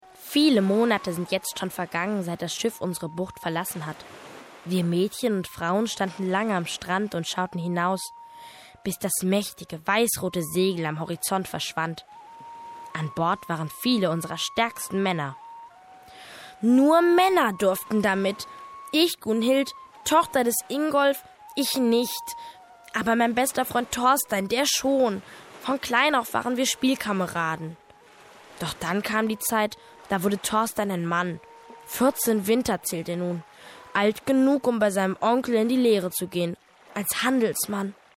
junge deutsche Sprecherin für Rundfunksendungen und Hörspiele.
Junge Stimme
Sprechprobe: Werbung (Muttersprache):
young female german voice over artist